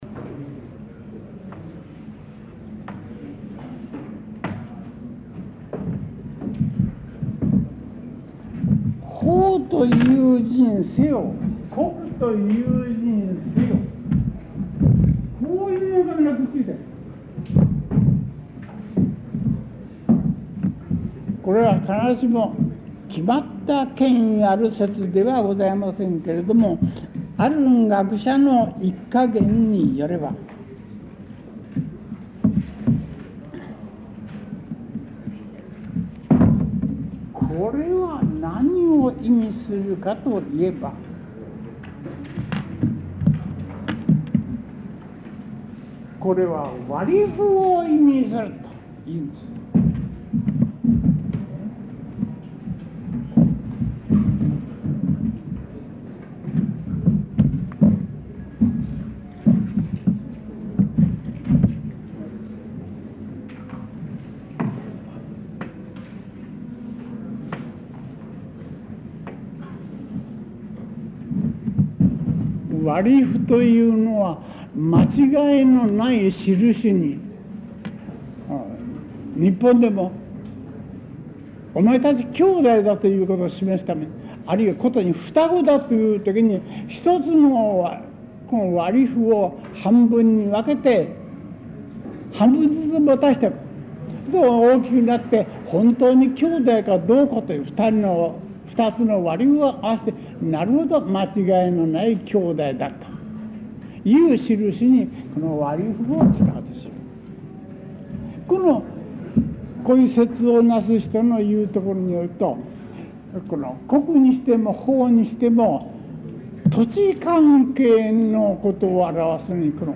講義録音テープ 11 | NDLサーチ | 国立国会図書館
テープ種別: Sony-SuperA Type5-90